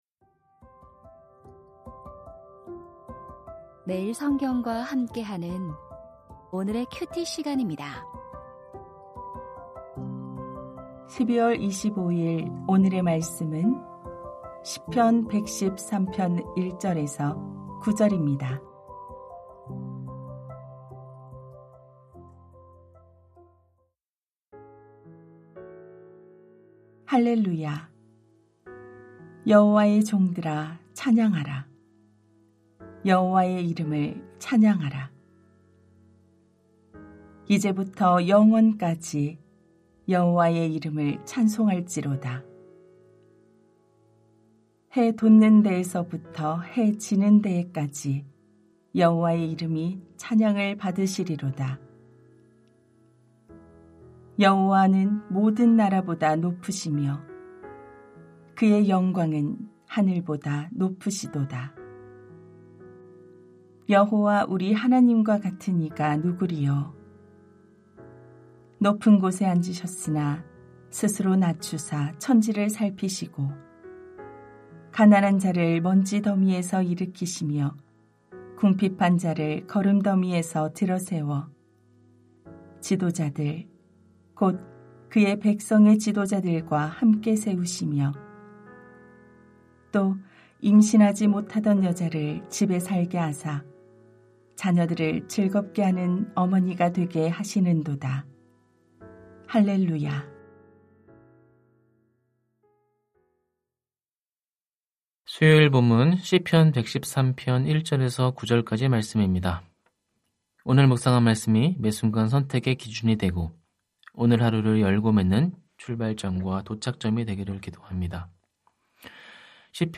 오디오 새벽설교 말씀